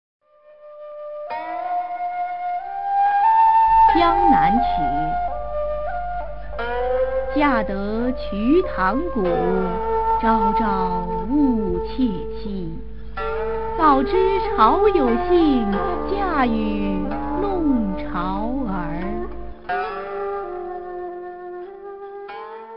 [隋唐诗词诵读]李益-江南曲a 配乐诗朗诵